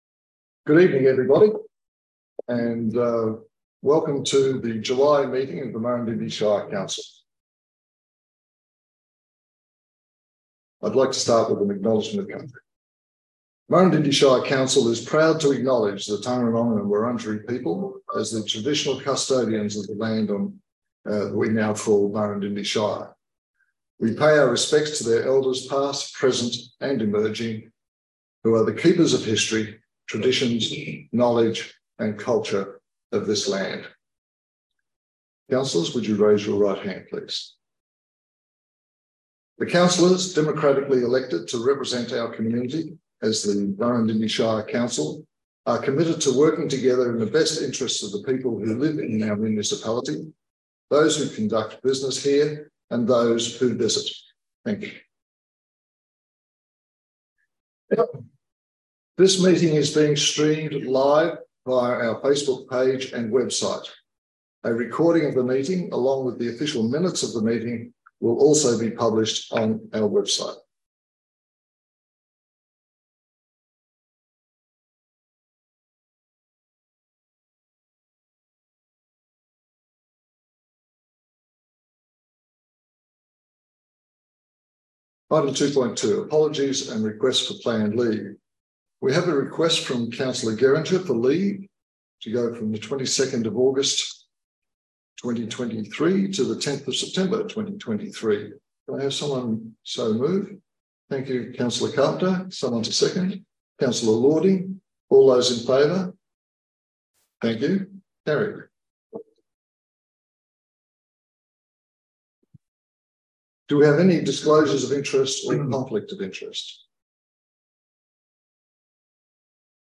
26 July 2023 Scheduled Meeting
Agenda, Minutes and Attachments for the Scheduled Meeting of Council on 26 July 2023.